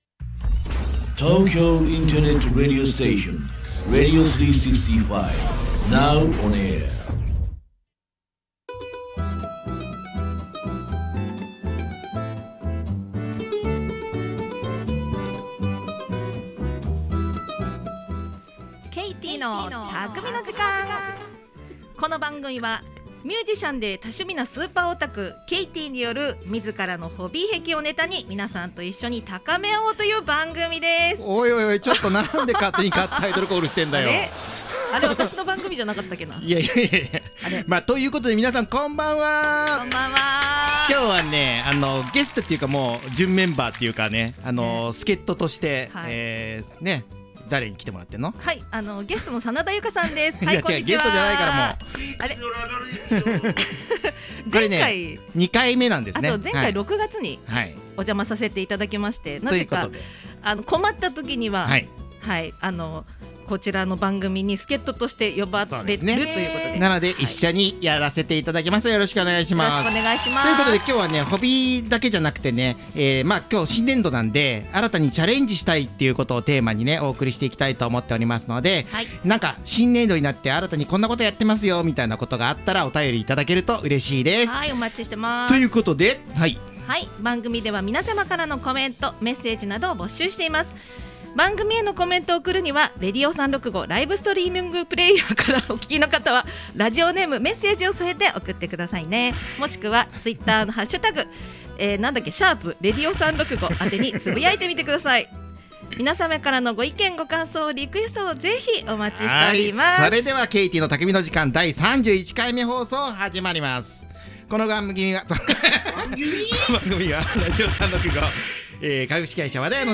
そんな新生活の中で、新たにチャレンジしたいことなども出てくる時期ではありますので、今日はホビーだけに限らず、新年度新たにチャレンジしたいことをテーマにお送りしていきます。 【この音源は生放送のアーカイブ音源となります】